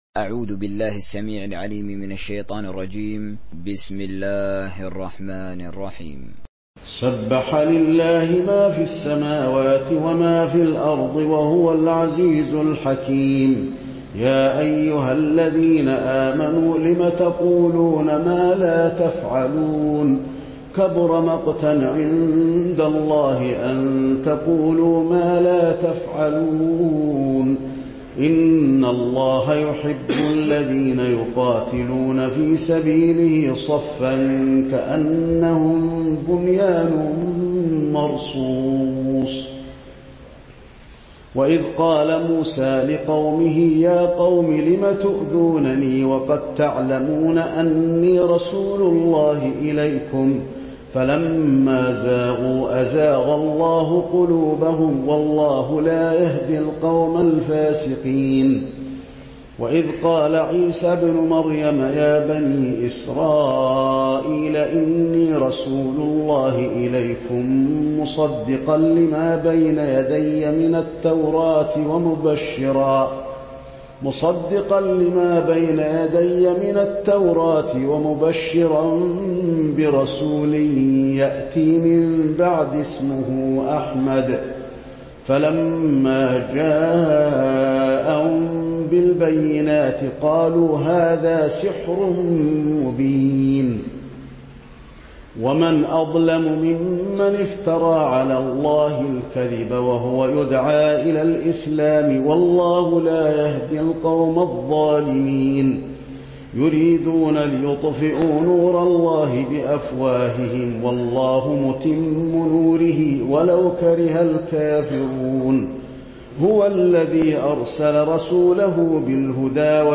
(Riwayat Hafs)